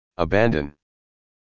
單獨英文發音是這樣(美國男聲)